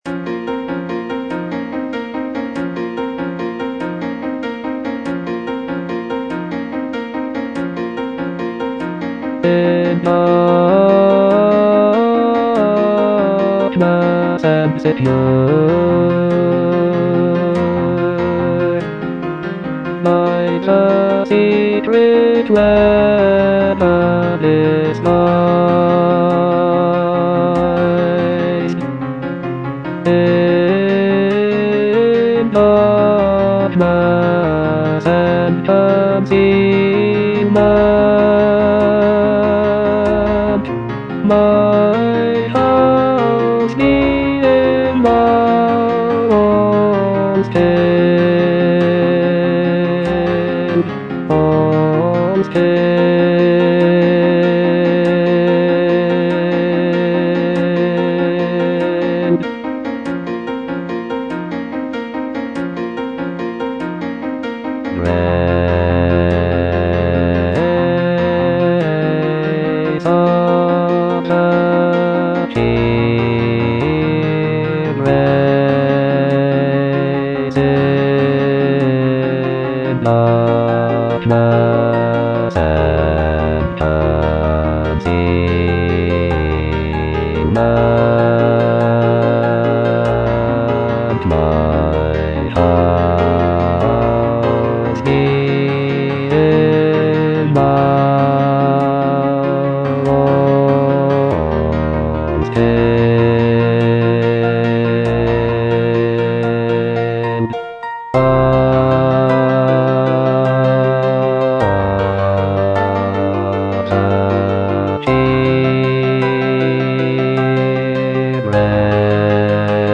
(bass II) (Voice with metronome) Ads stop